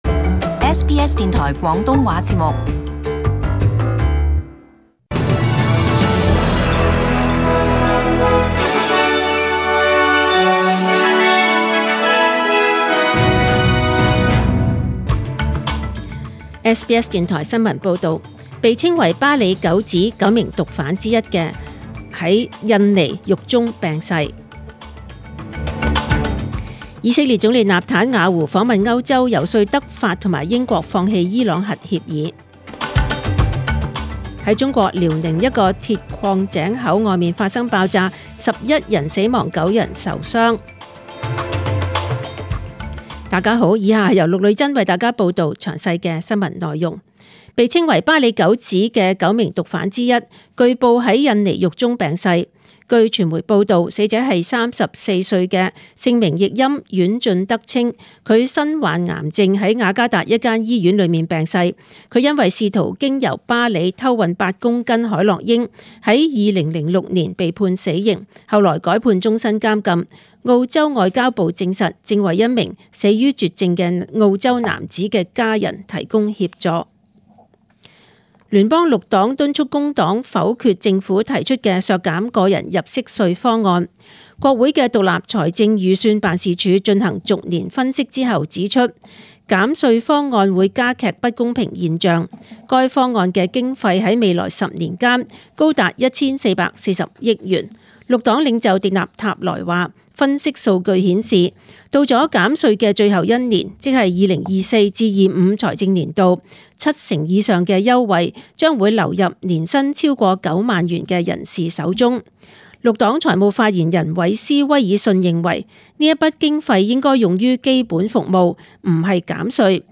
SBS中文新闻 （六月六日）
请收听本台为大家准备的详尽早晨新闻。